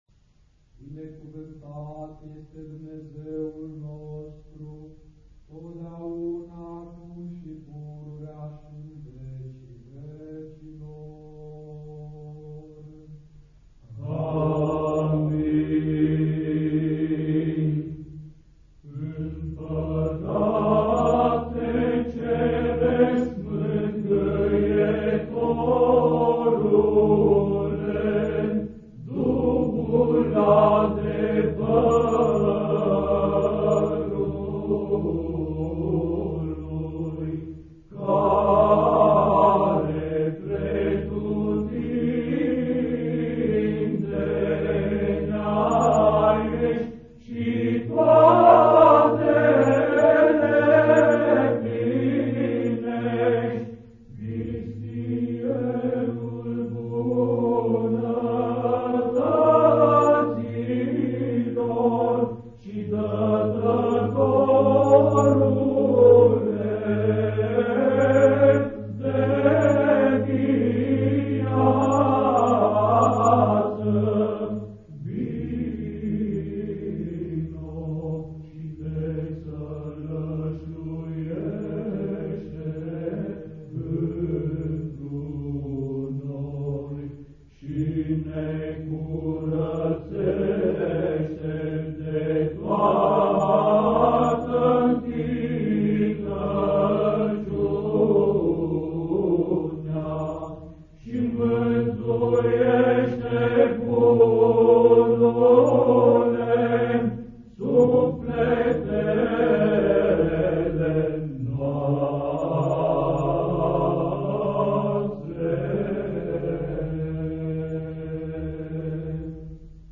Musica Ecclesiastica Rumena
Grupul Psaltic al Bisericii Stavropoleos